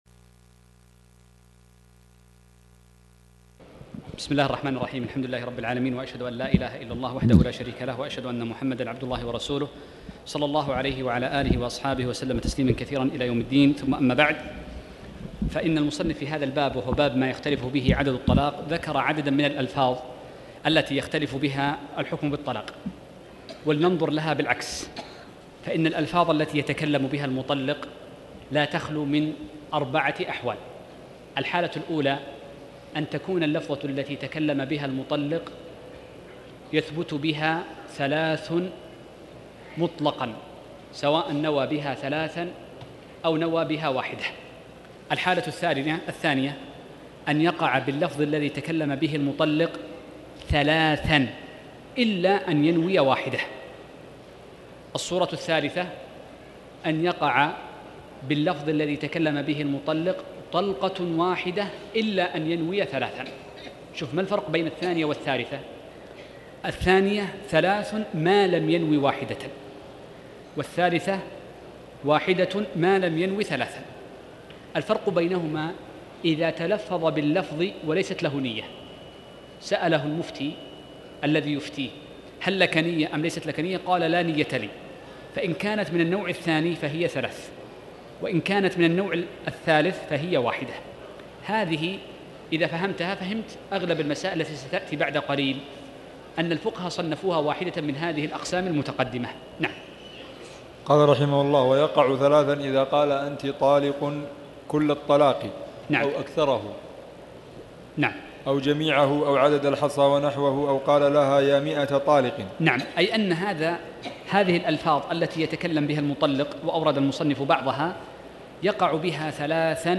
تاريخ النشر ١٠ جمادى الأولى ١٤٣٩ هـ المكان: المسجد الحرام الشيخ